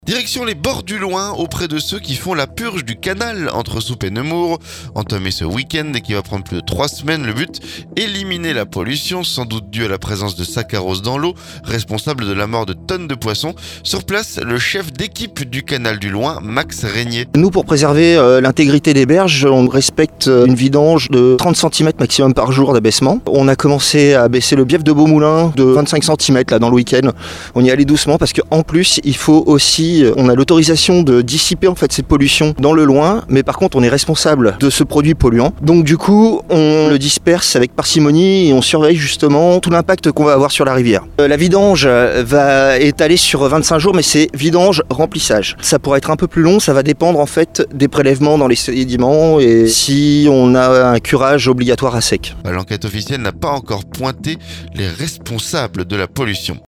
Direction les bords du Loing, auprès de ceux qui font la purge du canal entre Souppes et Nemours.